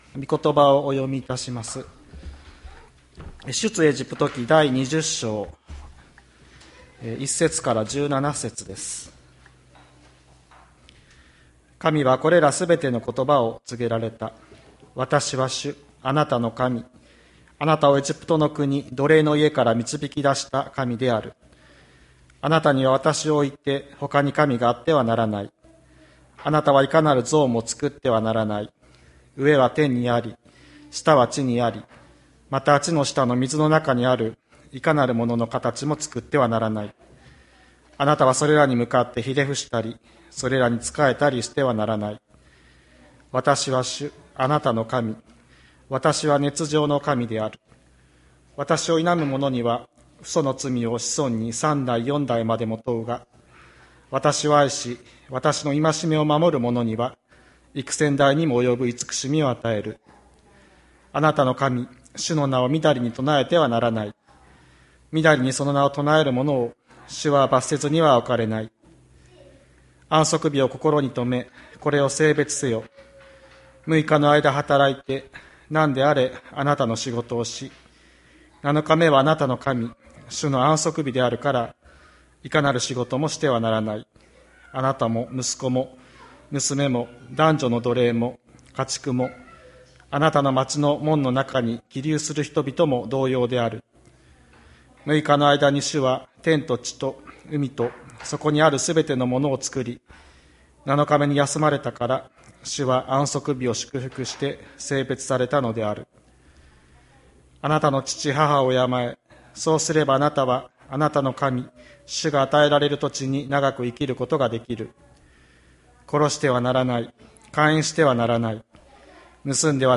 千里山教会 2021年10月24日の礼拝メッセージ。